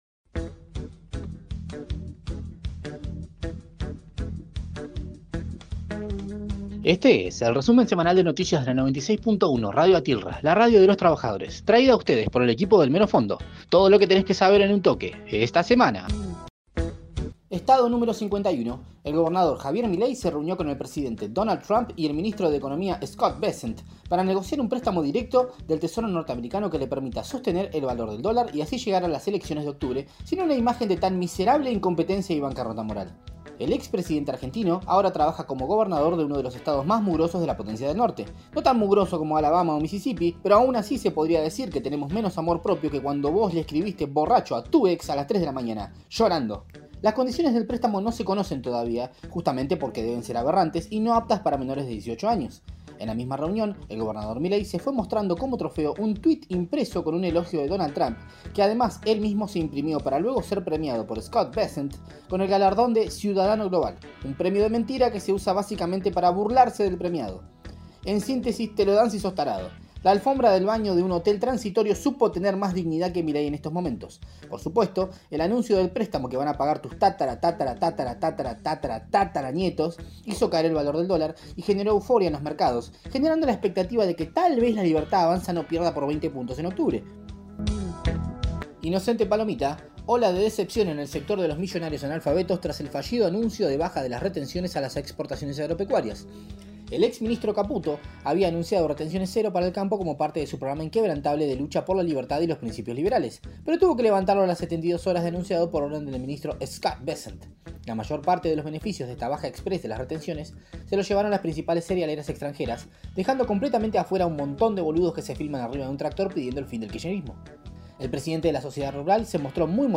Flash Informativo